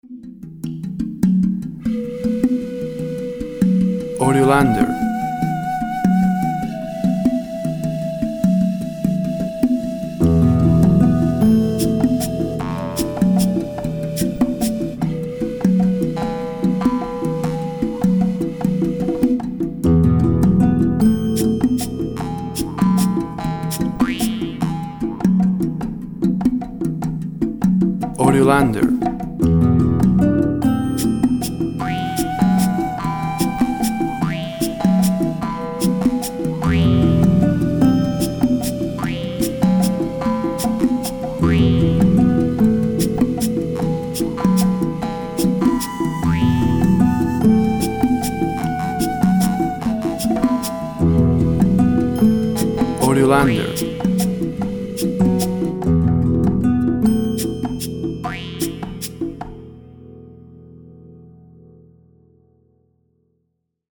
Ethnic and folks sound from the deep of the south america.
Tempo (BPM) 100